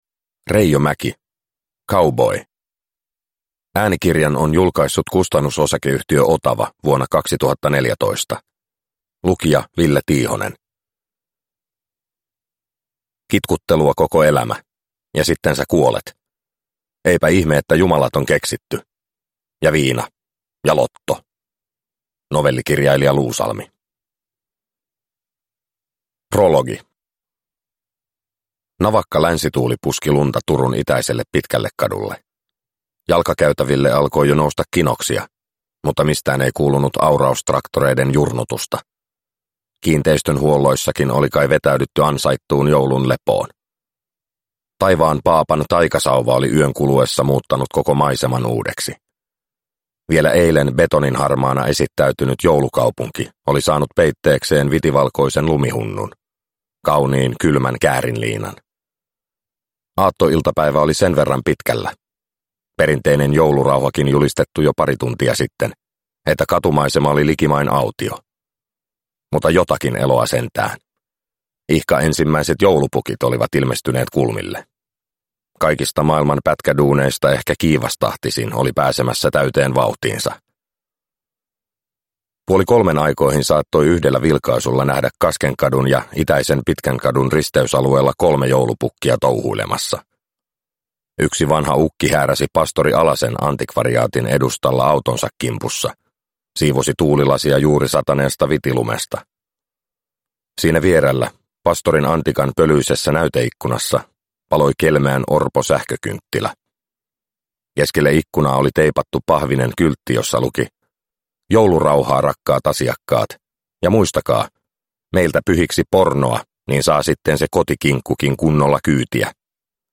Cowboy – Ljudbok – Laddas ner